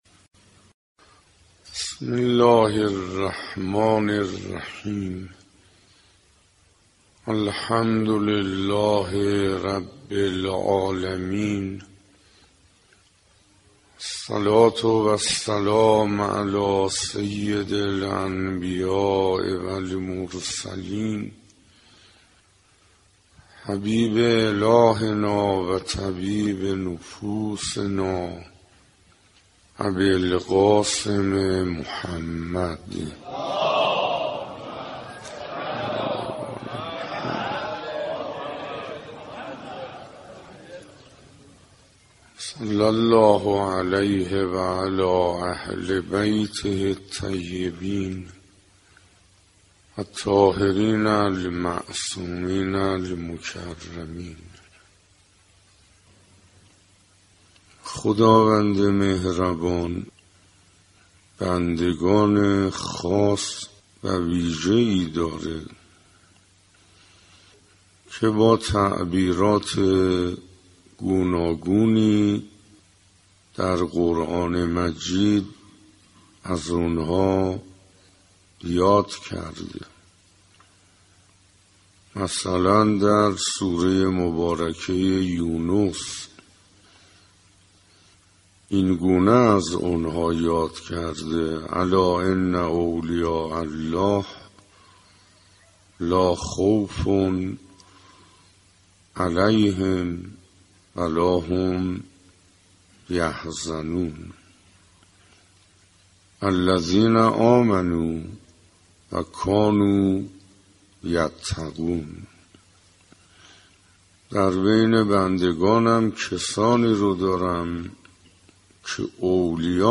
دانلود شانزدهمین جلسه از بیانات آیت الله حسین انصاریان با عنوان «ارزشهای ماه رمضان»